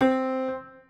Piano - Harpsichord.wav